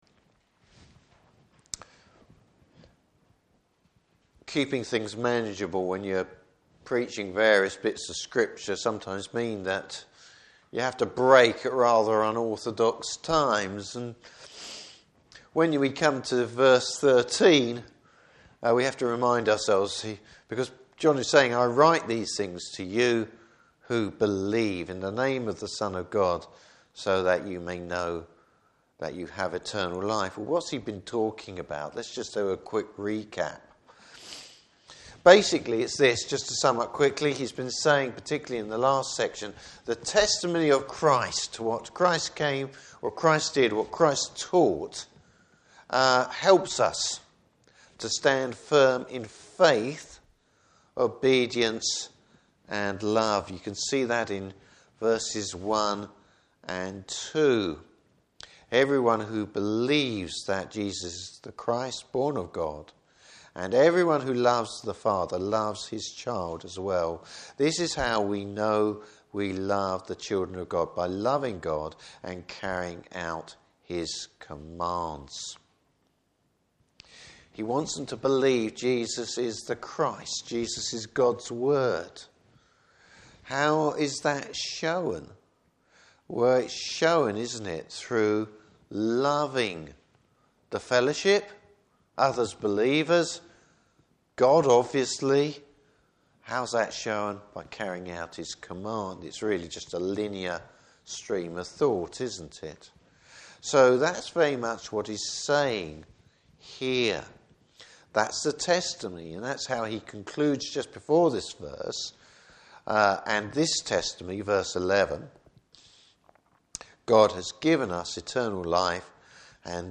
Service Type: Evening Service Bible Text: 1 John 5:13-21.